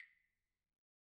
Claves1_Hit_v1_rr2_Sum.wav